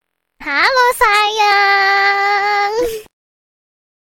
Kategori: Suara manusia
Nada sapaan manis dan romantis ini cocok banget buat dijadikan nada dering atau notif WA spesial dari orang tersayang.